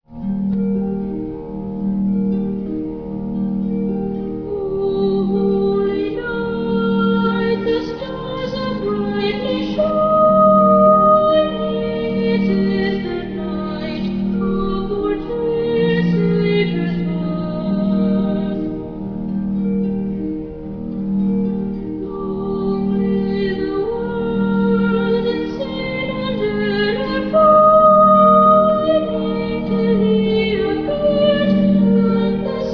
boy soprano
harp
organ.